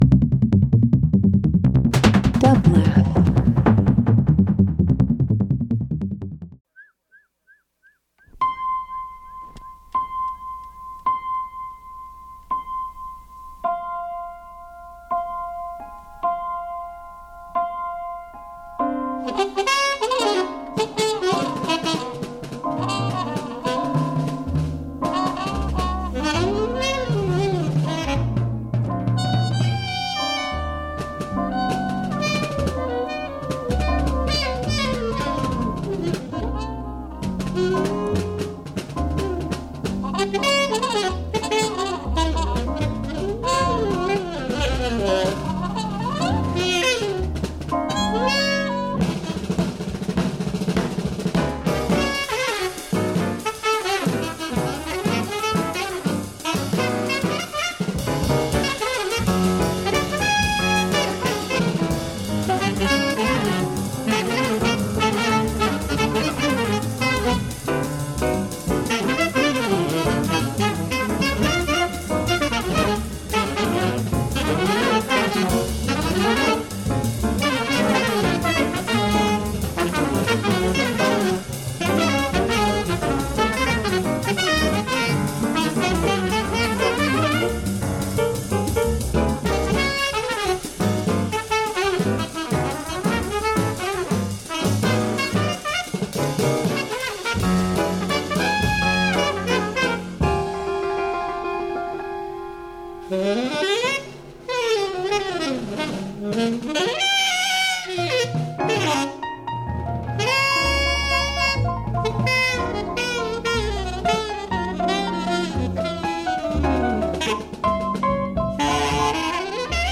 Drum & Bass Electronic Jazz Jungle